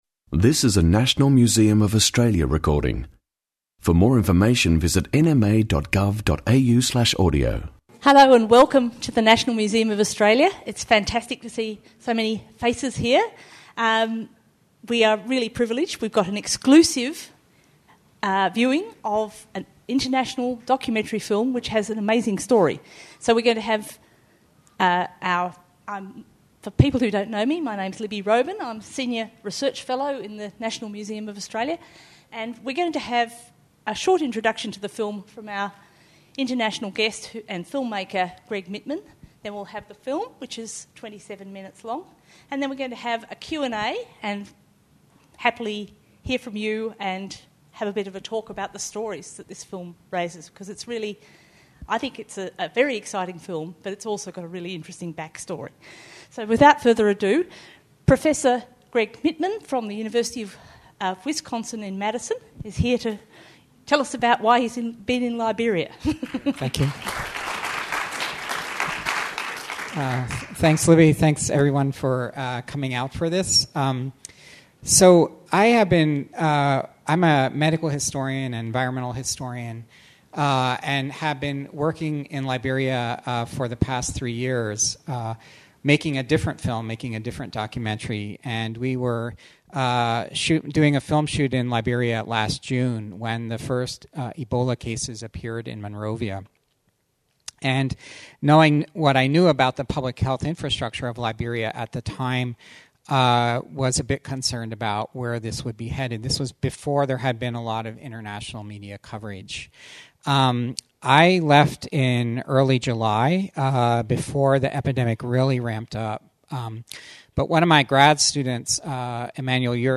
In the Shadow of Ebola film screening and discussion | National Museum of Australia